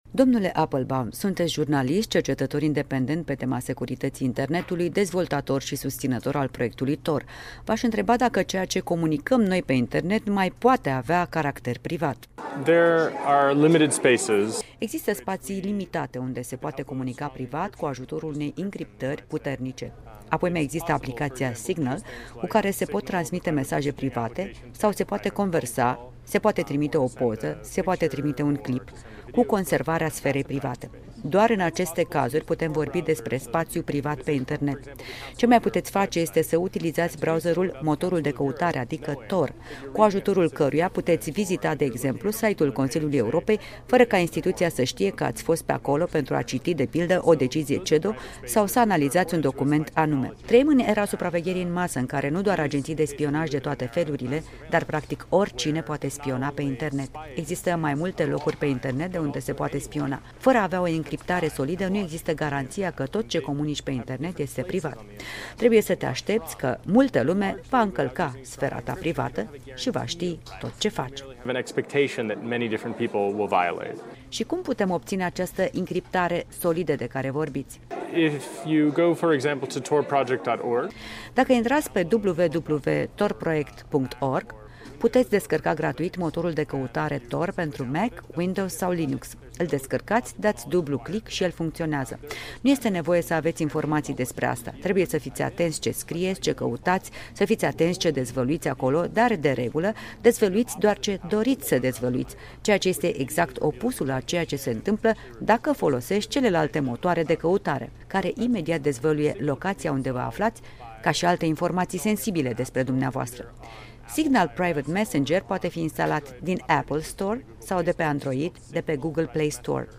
Interviul cu Jacob Appelbaum